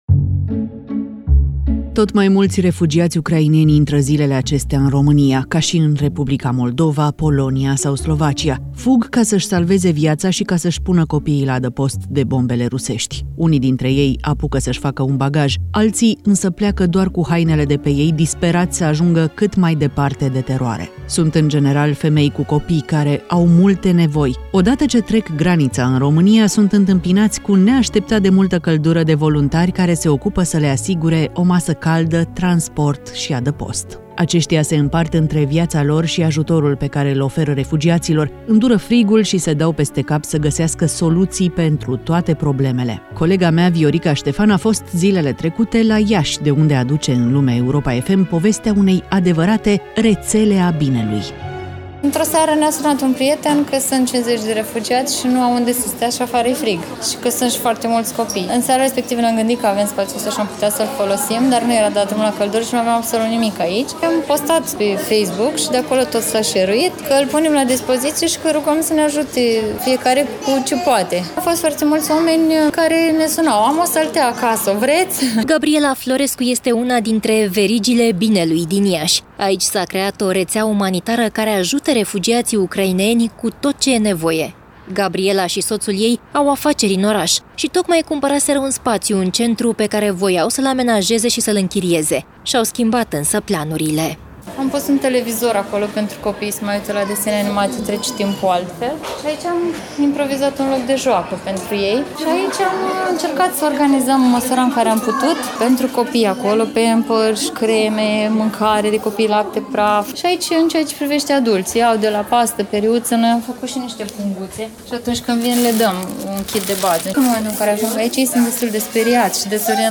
Lumea Europa FM: Rețeaua binelui la Iași | REPORTAJ